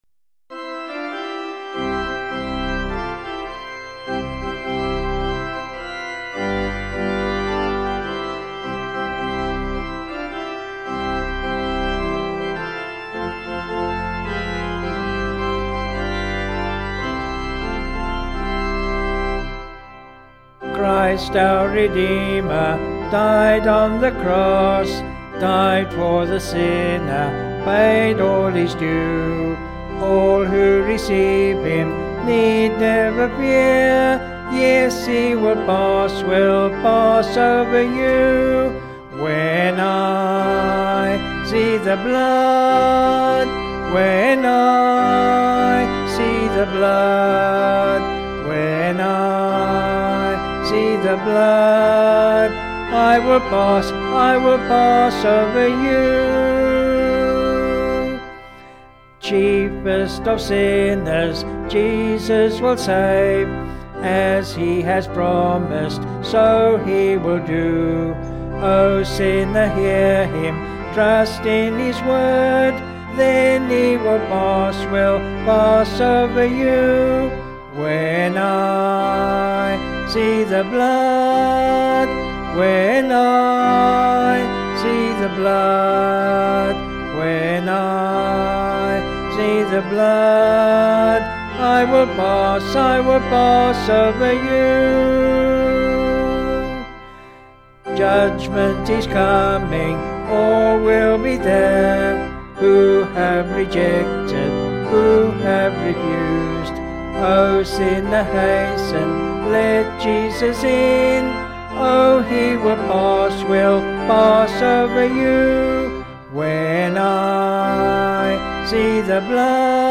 Vocals and Organ   263.6kb Sung Lyrics